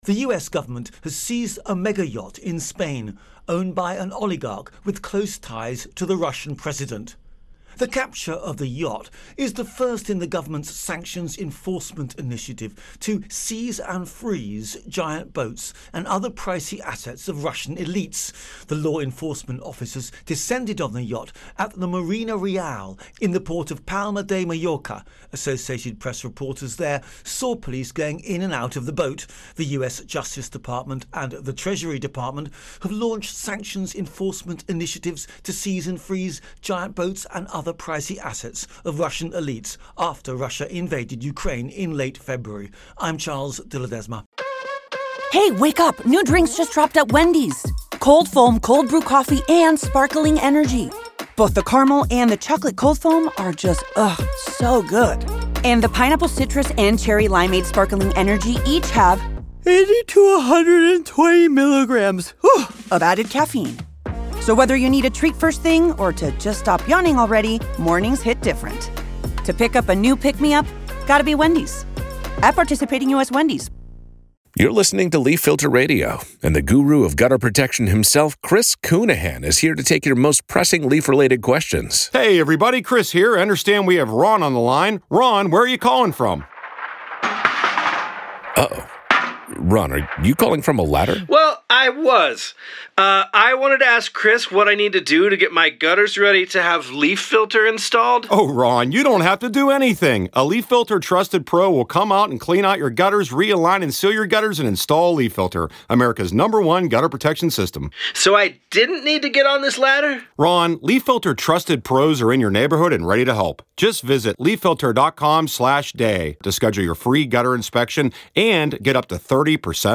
Oligarch's Yacht Sanctions Intro and Voicer